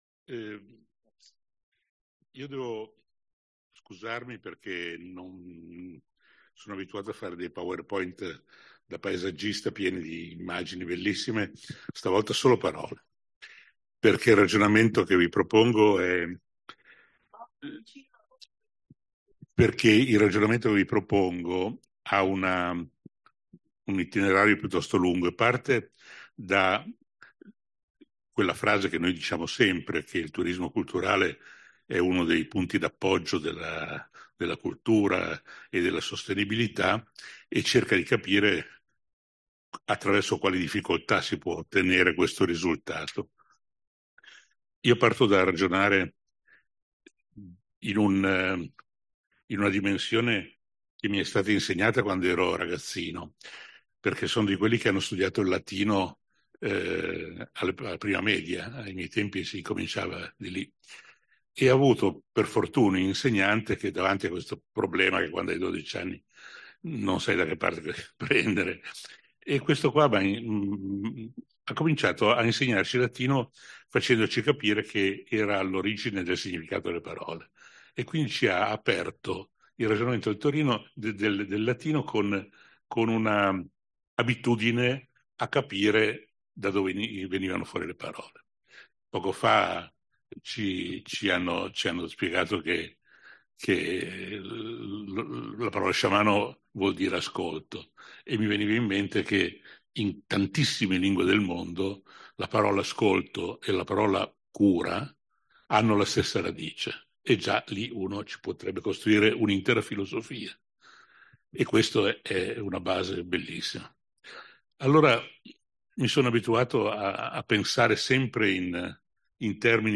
Qui il podcast dell’intervento al WTE di Genova 2024